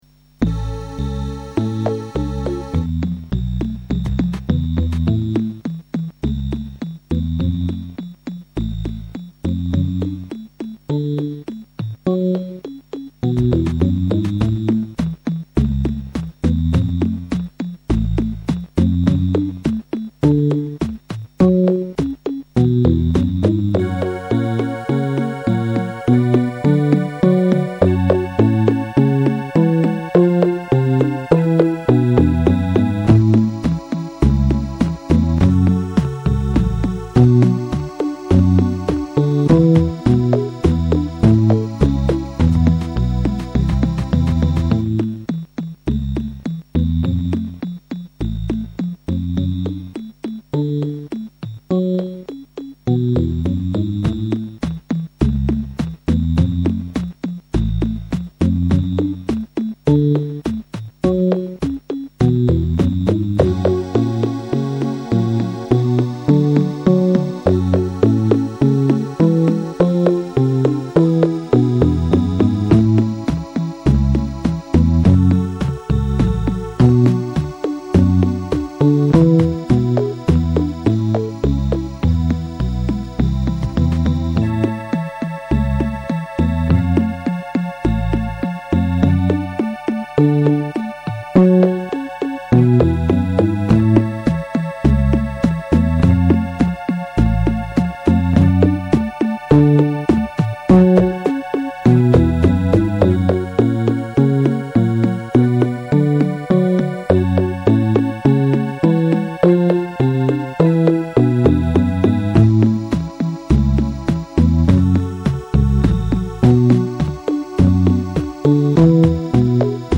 LucyTuned song